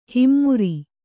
♪ himmuri